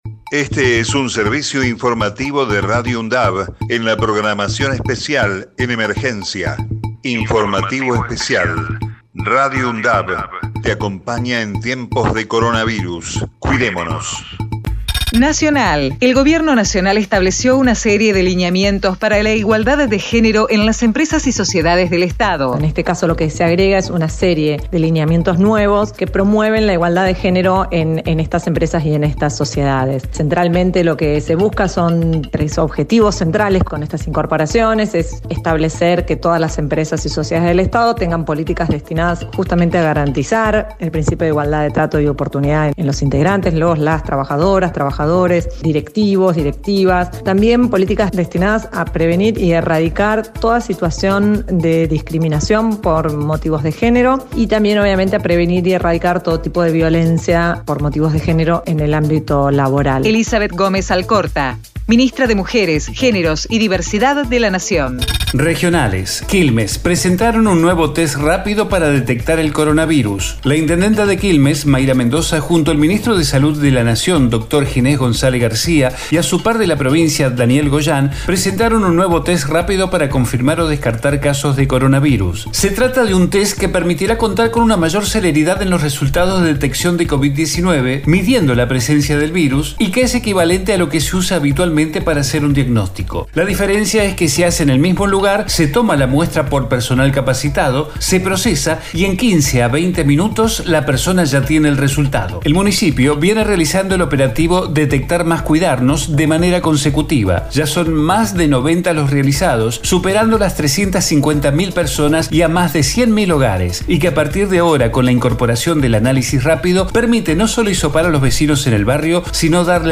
COVID-19 Informativo en emergencia 25 de septiembre 2020 Texto de la nota: Este es un servicio informativo de Radio UNDAV en la programación especial en emergencia. Radio UNDAV te acompaña en tiempos de coronavirus, cuidemonos.